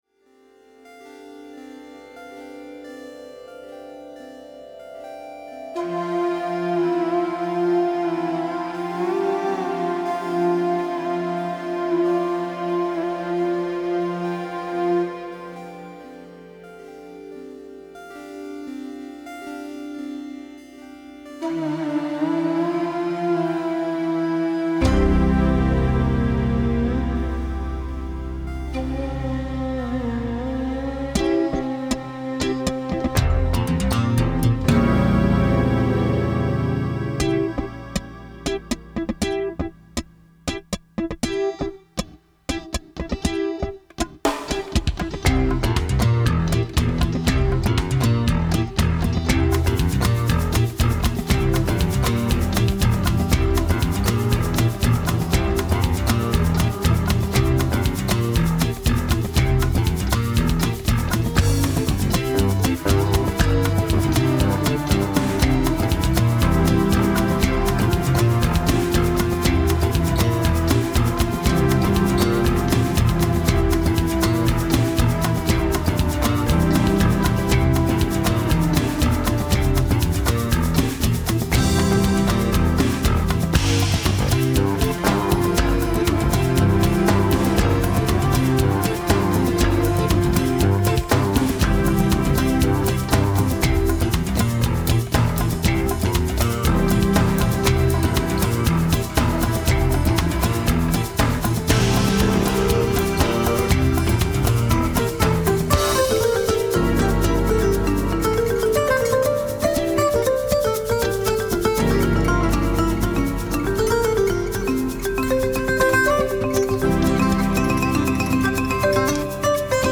Synthesizers, acoustic and electric guitars, bass guitar, percussion and melodic percussion.